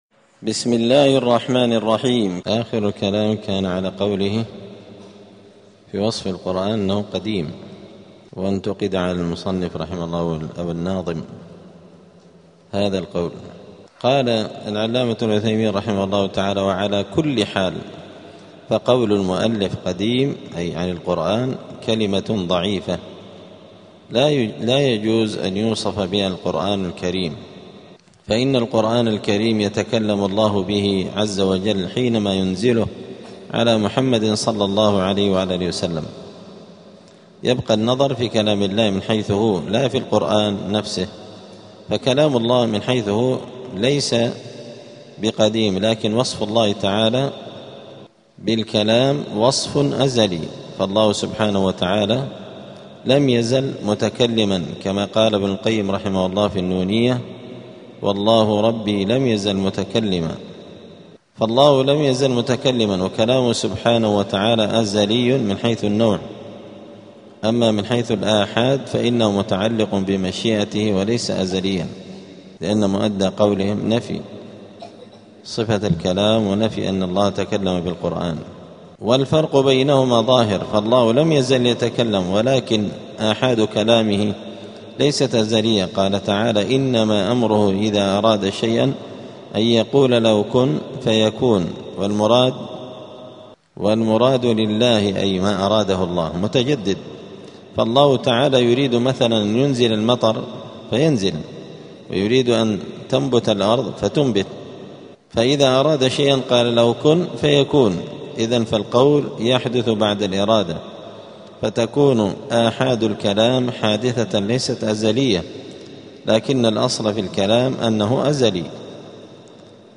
دار الحديث السلفية بمسجد الفرقان قشن المهرة اليمن
40الدرس-الأربعون-من-شرح-العقيدة-السفارينية.mp3